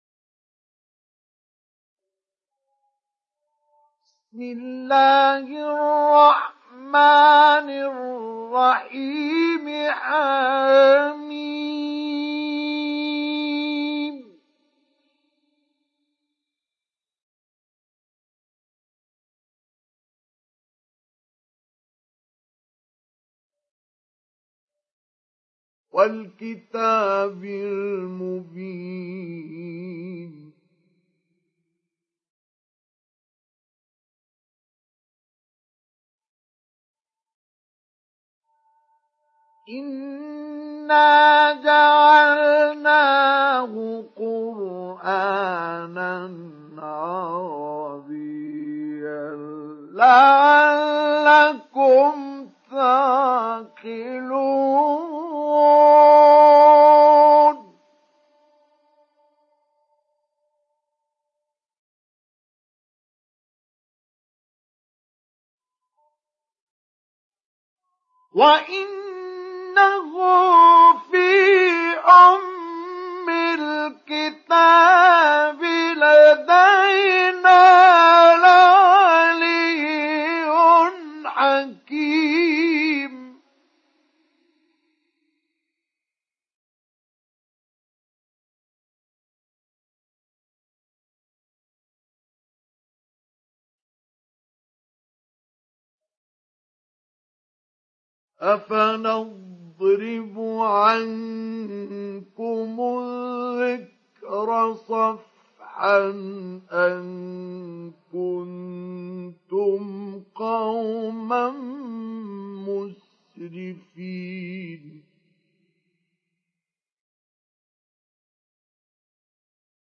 Surat Az Zukhruf Download mp3 Mustafa Ismail Mujawwad Riwayat Hafs dari Asim, Download Quran dan mendengarkan mp3 tautan langsung penuh
Download Surat Az Zukhruf Mustafa Ismail Mujawwad